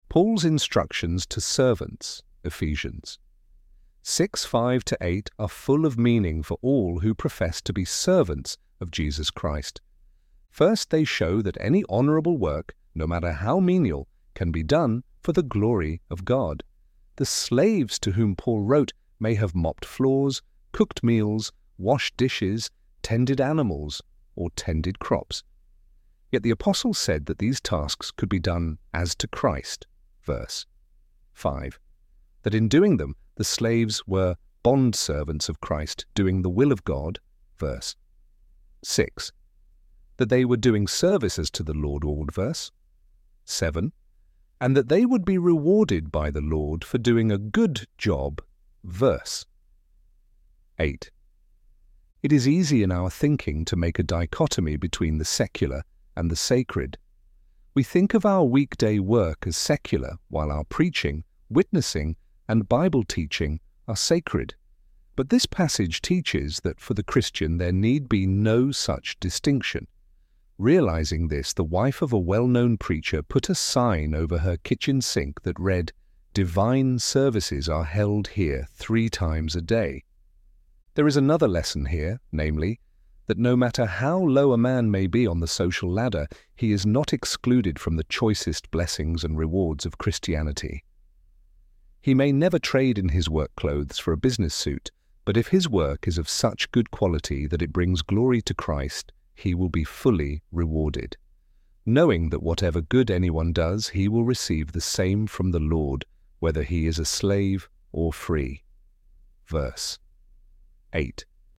ElevenLabs_Doing_Service_–_as_to_the_Lord.mp3